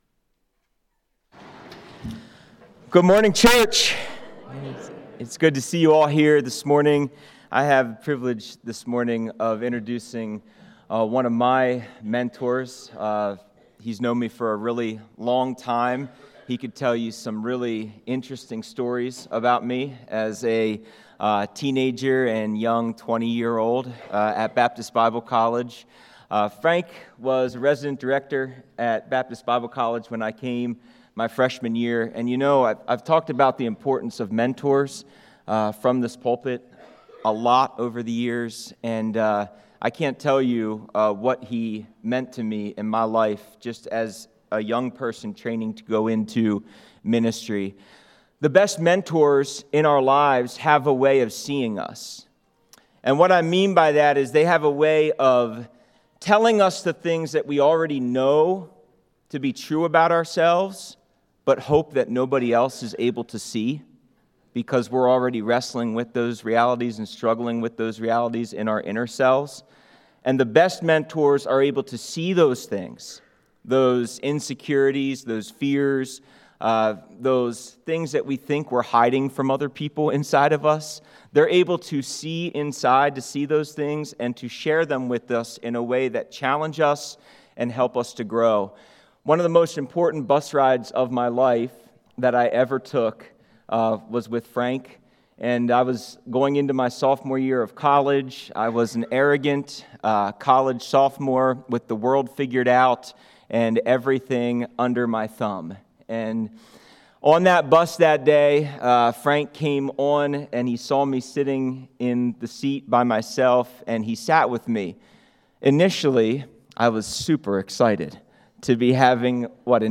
Sermon Notes
Global Outreach Conference 2026